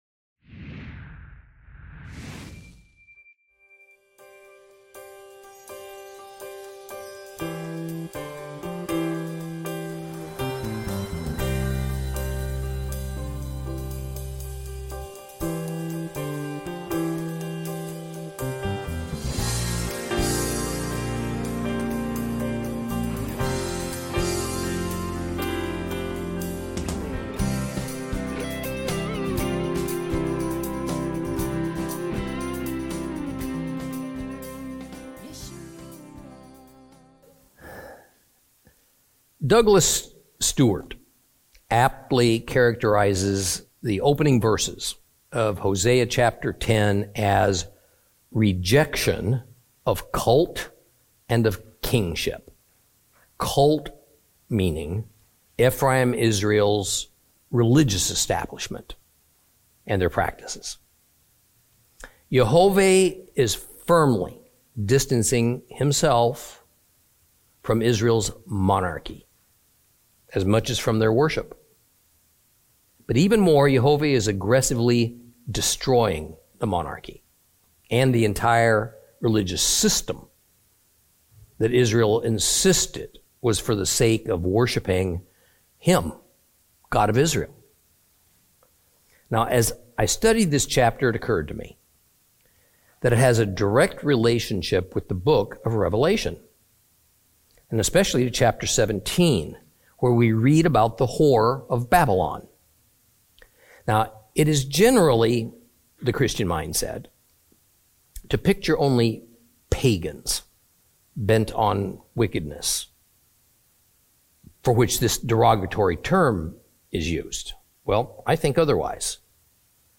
Teaching from the book of Hosea, Lesson 17 Chapter 10.